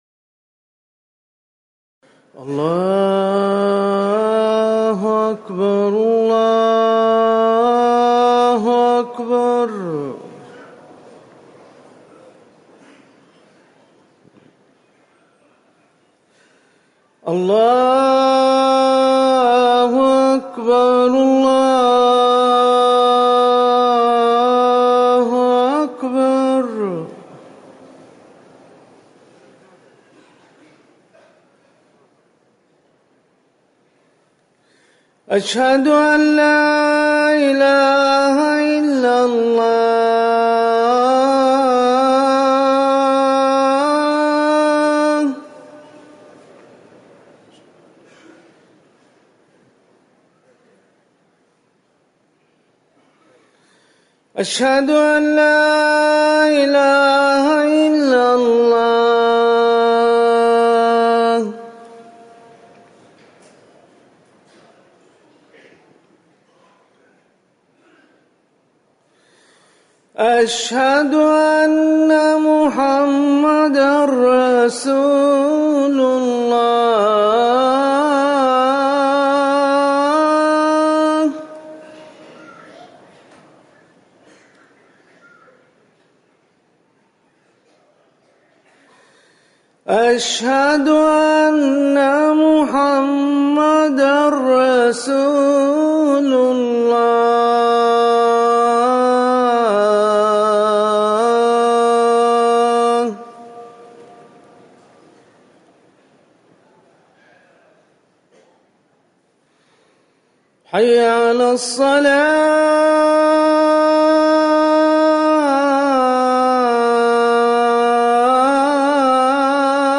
أذان العصر
تاريخ النشر ١٥ صفر ١٤٤١ هـ المكان: المسجد النبوي الشيخ